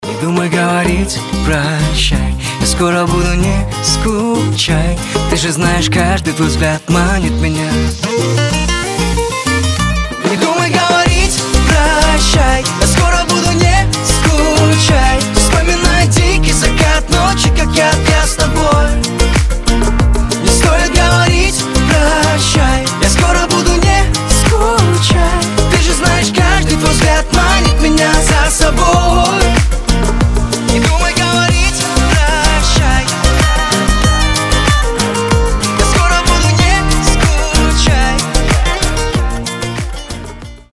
• Качество: 320, Stereo
поп
гитара
душевные
теплые